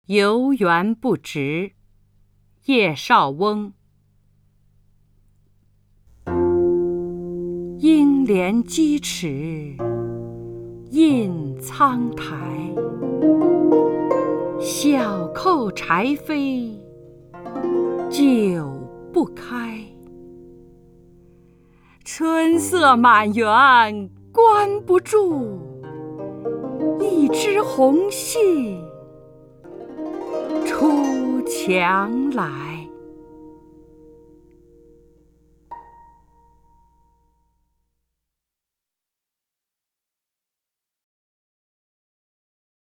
张筠英朗诵：《游园不值》(（南宋）叶绍翁) （南宋）叶绍翁 名家朗诵欣赏张筠英 语文PLUS
（南宋）叶绍翁 文选 （南宋）叶绍翁： 张筠英朗诵：《游园不值》(（南宋）叶绍翁) / 名家朗诵欣赏 张筠英